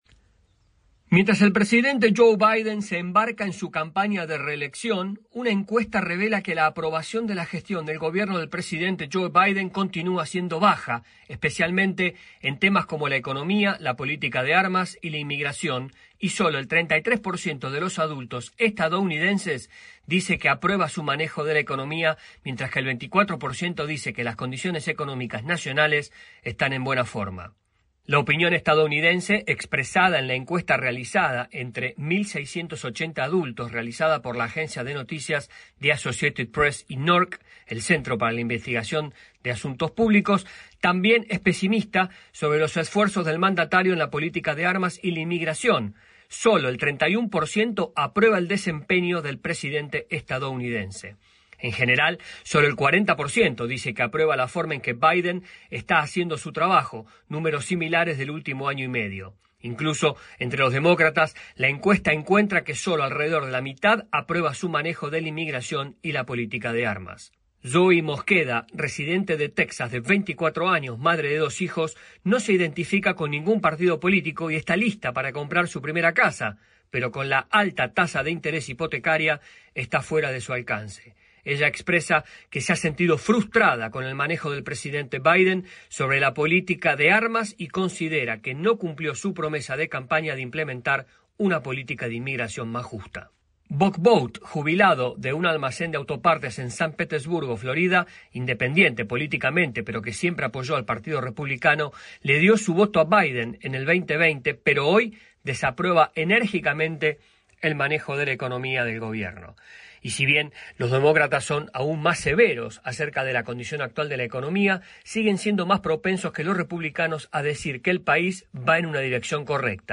AudioNoticias
desde la Voz de América en Washington DC